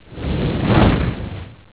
fireout.wav